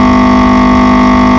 Buggy_High.wav